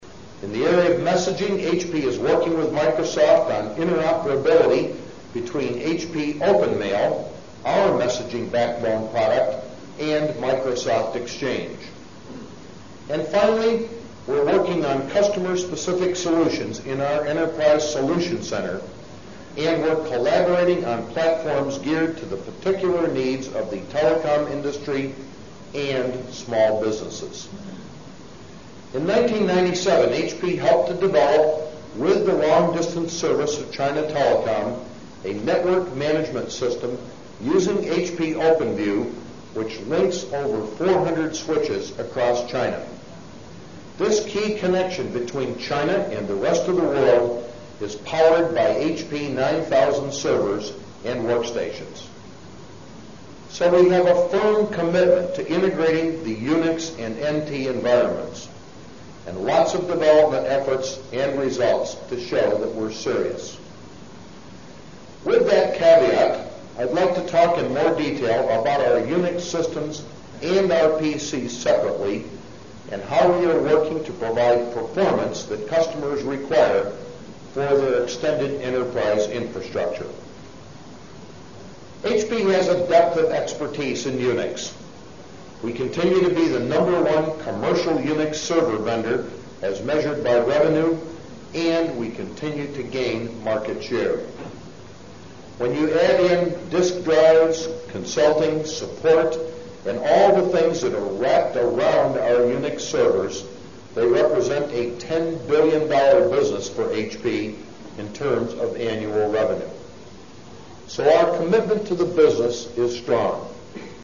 财富精英励志演讲 第152期:携手打造电子化世界(4) 听力文件下载—在线英语听力室